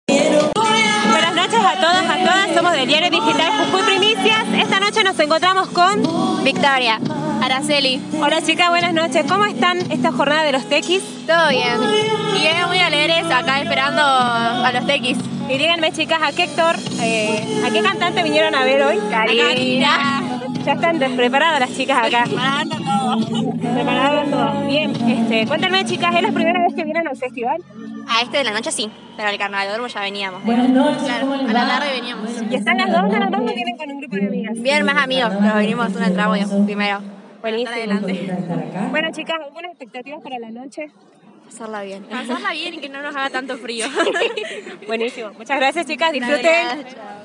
En este sentido, muchas personas confirmaron que fue asistían al Evento solo por la Cantante; al respecto, tuvimos un diálogo con unas jóvenes que nos cuentan su experiencia previa al espectáculo:
Entrevista-1.mp3